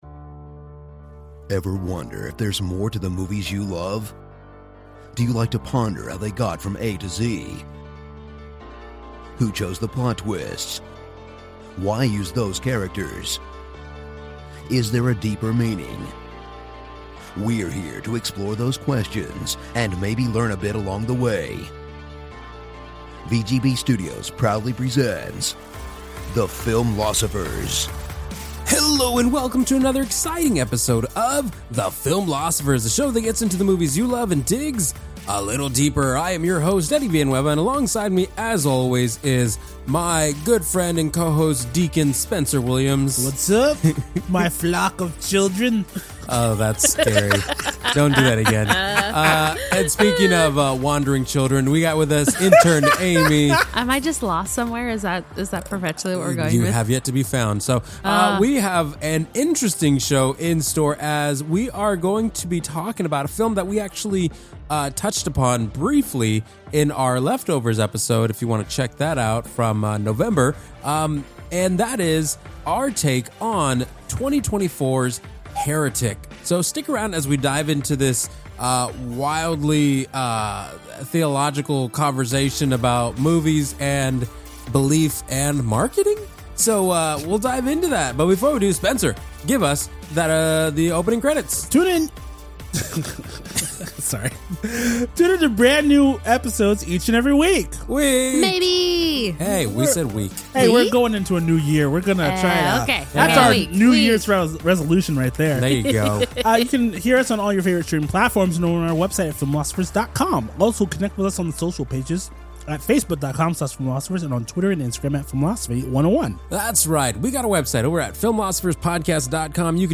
Join them for a (mostly) lighthearted discussion of faith, doubt, and the perils of door-to-door proselytizing.